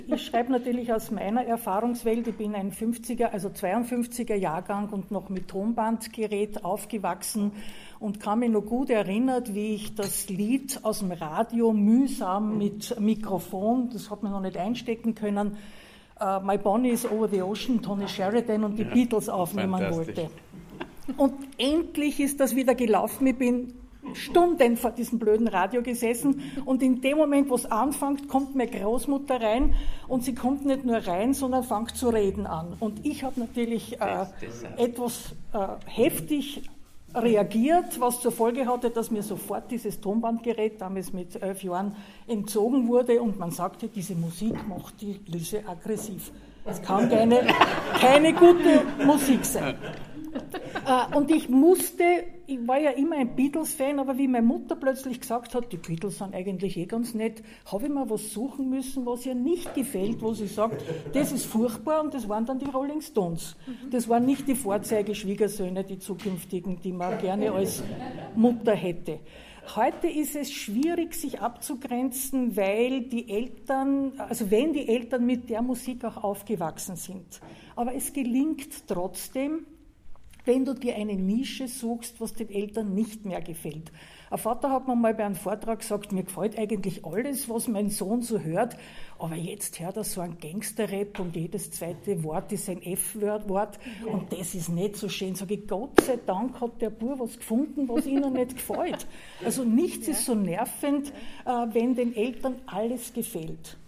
Die lebendige Diskussion geben auch die Hörbeispiele wider.